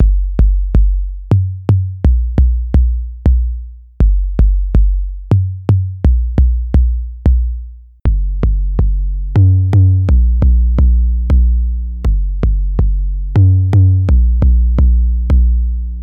couture-808-drive.mp3